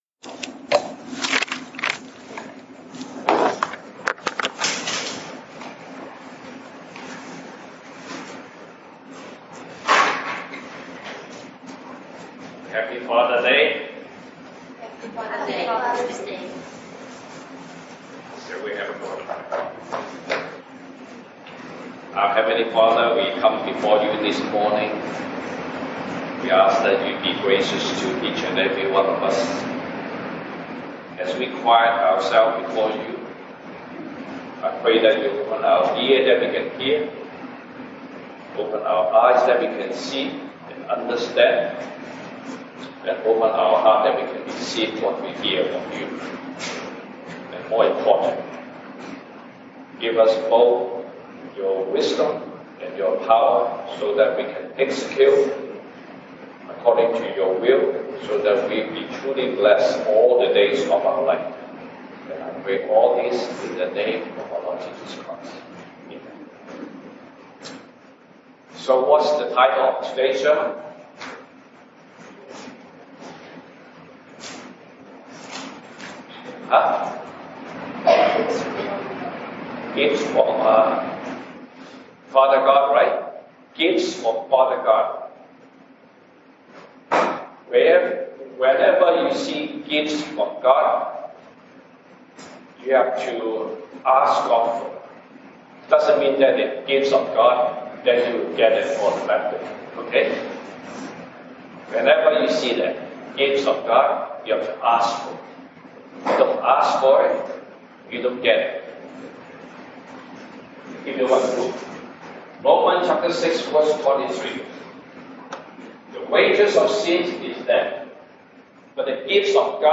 Sunday Service English